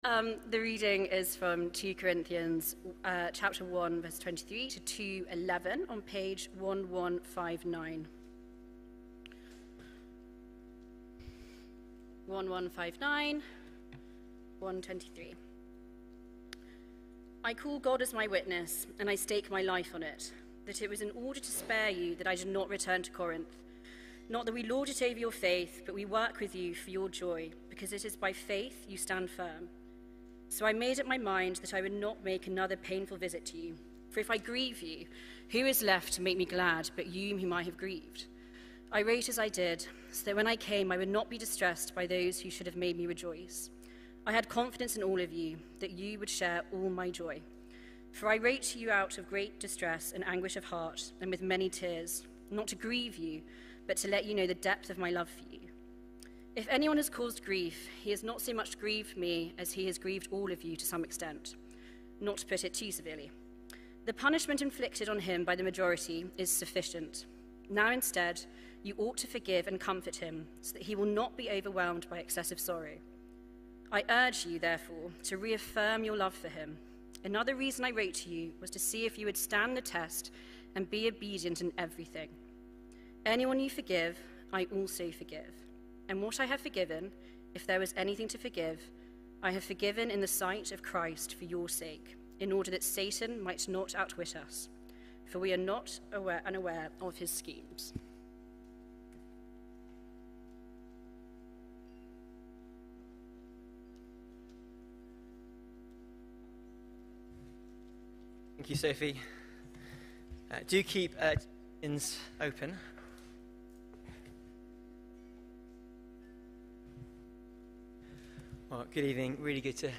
2 Corinthians 1:23-2:11 – PM Service – 8th February 2026